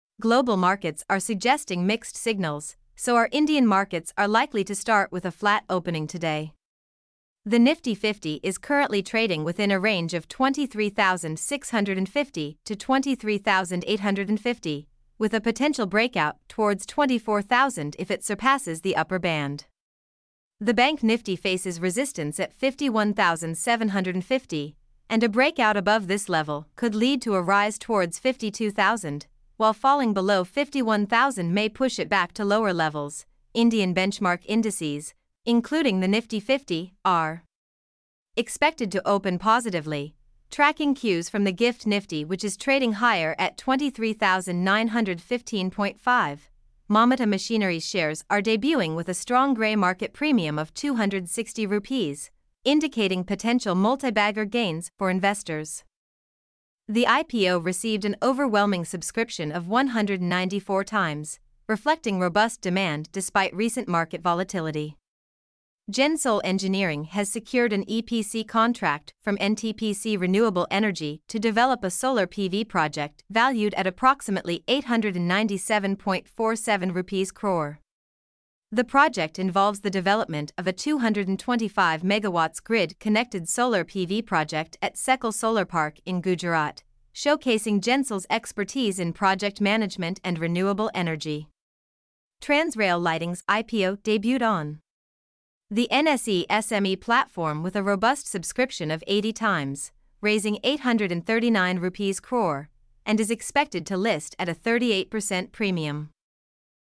mp3-output-ttsfreedotcom-39.mp3